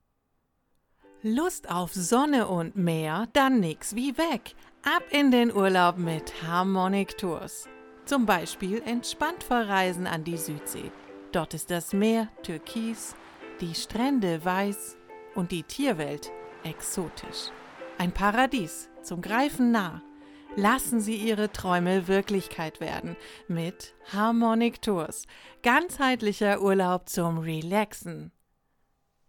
sprecherin
Demo Aufnahmen
Werbespot
Werbung_03.mp3